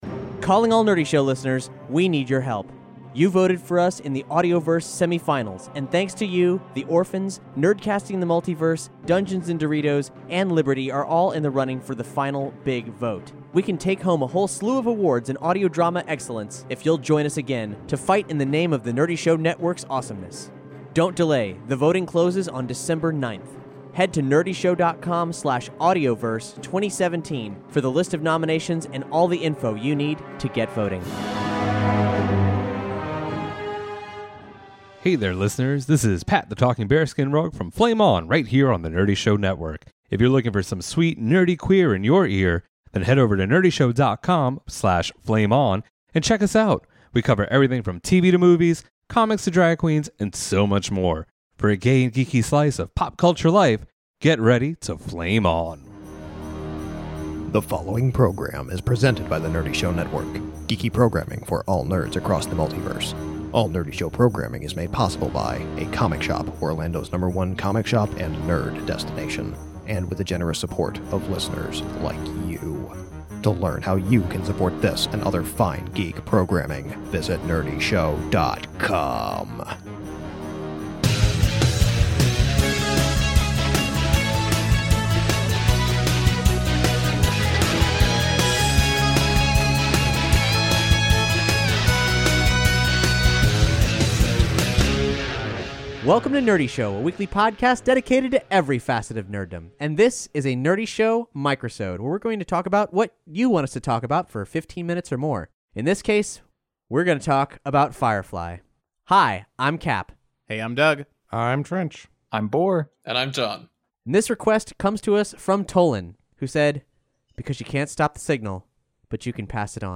four longtime fans and one nonplussed nerd who doesn't get the hype